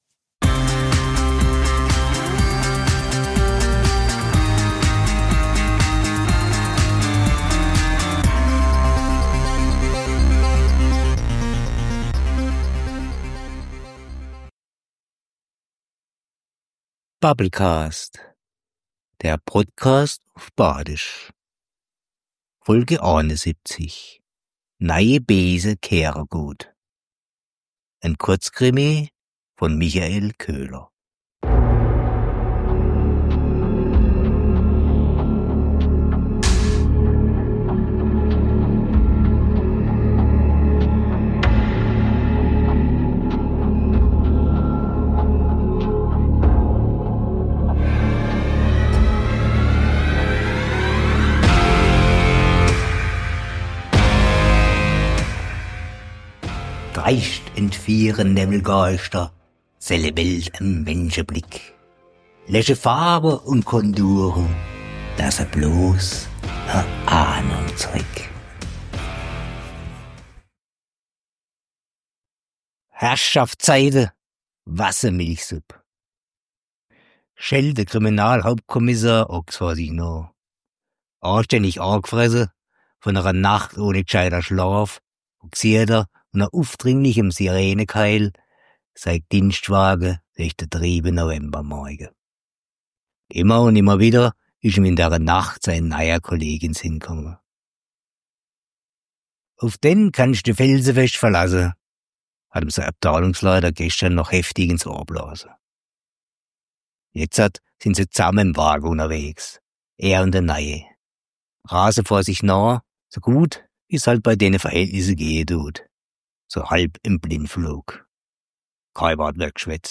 En Kurzkrimi uff Badisch.